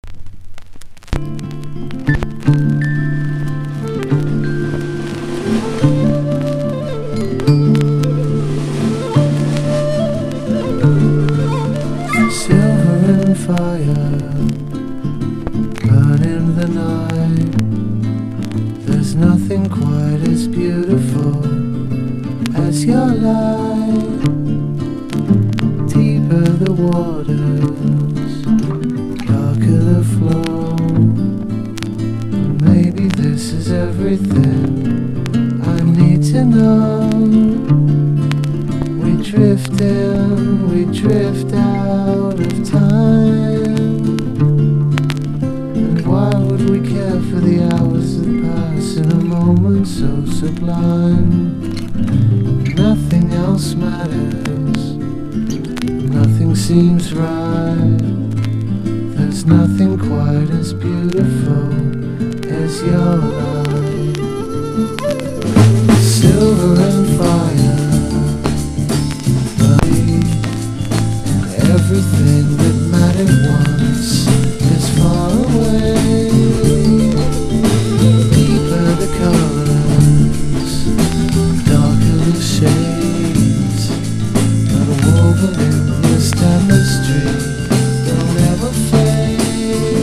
# SSW / FOLK (90-20’s) # NEO ACOUSTIC / GUITAR POP (90-20’s)
シンプルなサウンドながら全体を通して心地よい音が印象的で、気だるいボーカルが更に素晴らしいです！